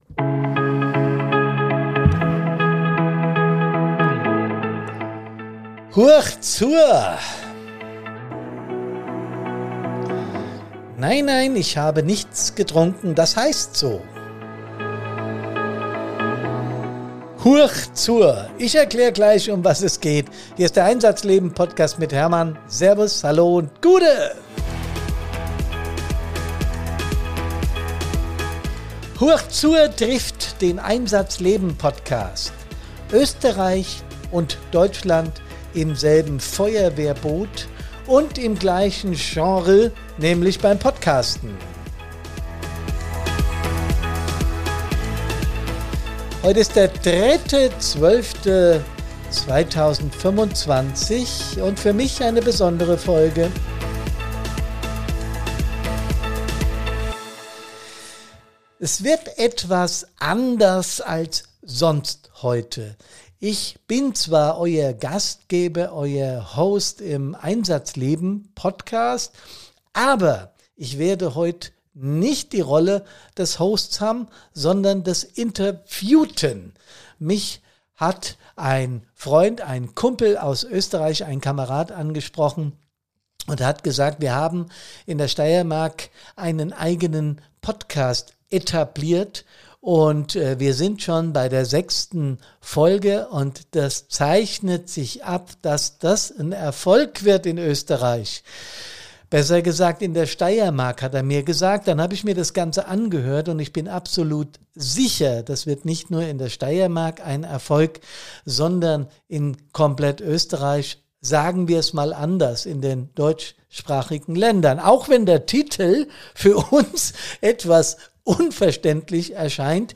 Ein Gespräch, das Mut macht, den Blick zu weiten und das Thema mentale Gesundheit in der Feuerwehr ernst zu nehmen.